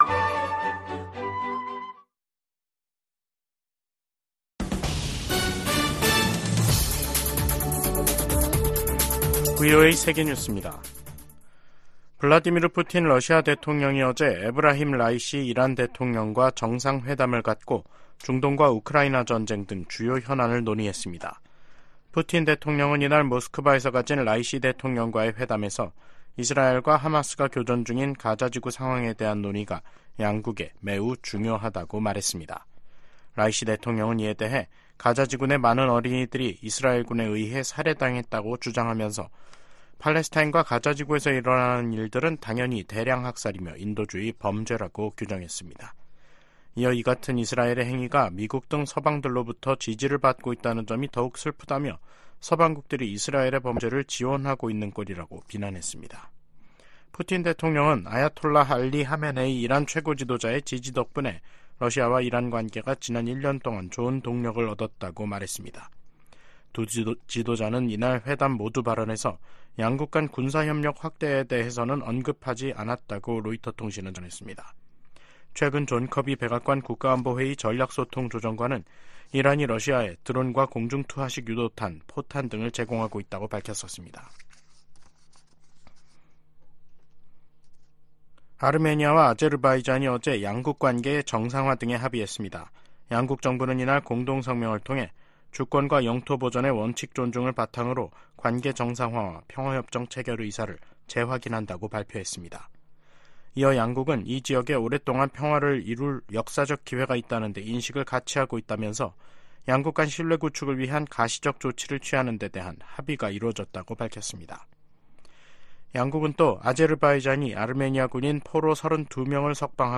VOA 한국어 간판 뉴스 프로그램 '뉴스 투데이', 2023년 12월 8일 2부 방송입니다. 커트 캠벨 미 국무부 부장관 지명자가 상원 인준청문회에서 대북 억지력 강화의 필요성을 강조했습니다. 북한 정권의 불법 사이버 활동을 차단하기 위한 미국과 한국, 일본의 외교 실무그룹이 공식 출범했습니다. 미 상·하원이 9천억 달러에 달하는 내년 국방수권법 최종안을 공개했습니다.